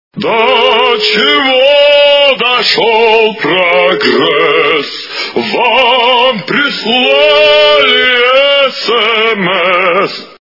» Звуки » звуки для СМС » До чего дошел прогресс! - Вам прислали СМС!
При прослушивании До чего дошел прогресс! - Вам прислали СМС! качество понижено и присутствуют гудки.